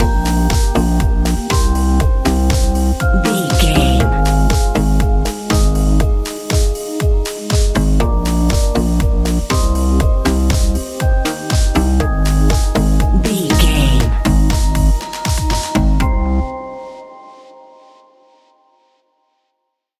Aeolian/Minor
synthesiser
drum machine
sleigh bells